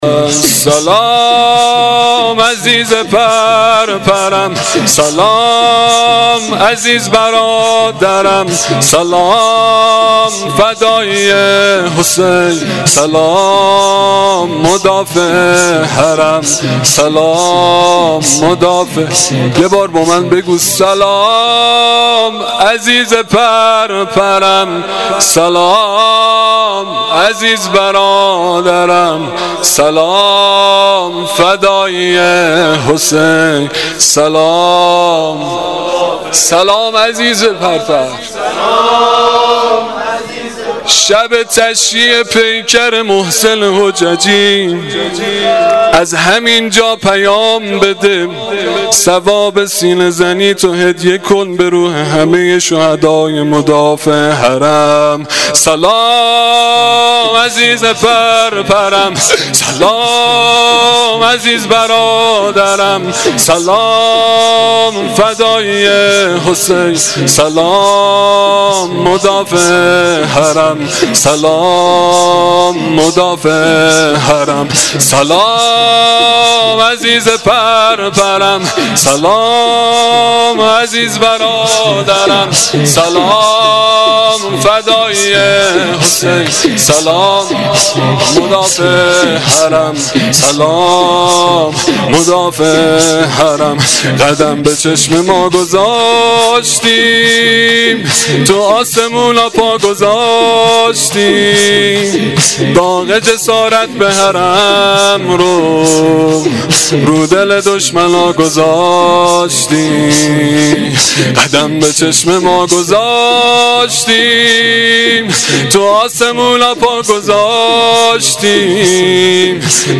شور شهدایی شب ششم محرم الحرام 1396
• Shabe06 Moharram1396[09]-Shoor.mp3